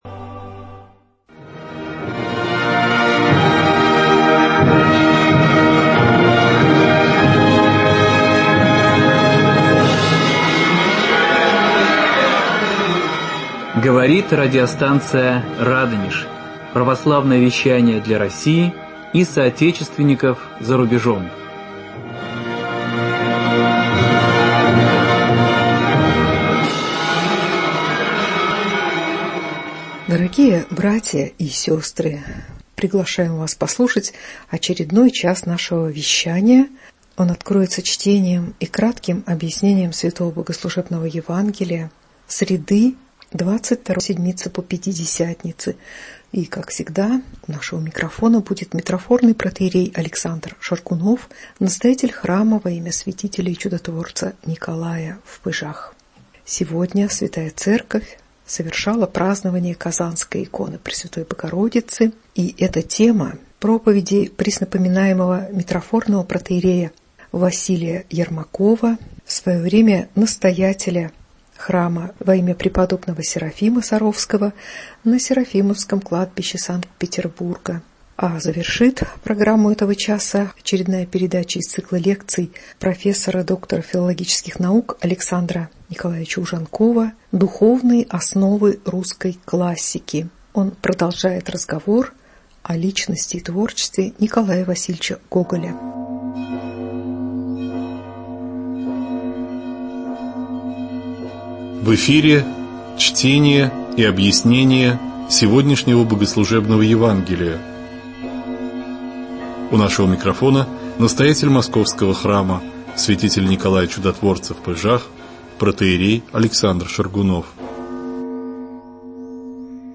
Слово
в праздник Казанской иконы Богородицы